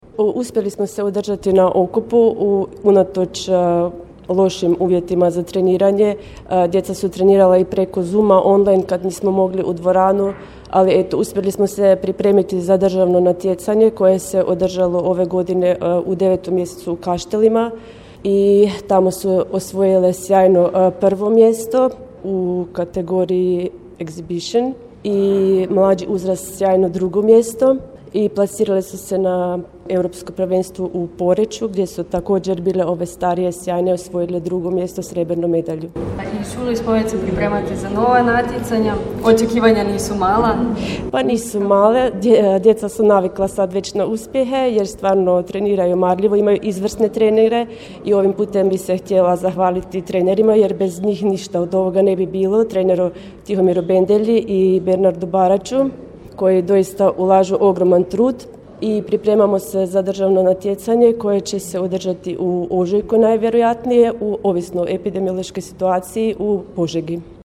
Državne prvakinje i doprvakinje, ali i europske viceprvakinje na prijemu kod načelnika